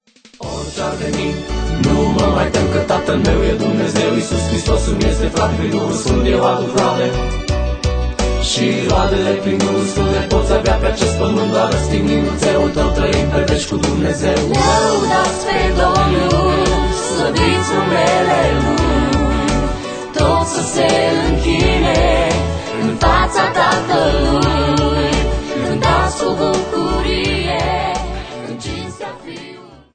Personalitate, forta, dinamism, energie si originalitate.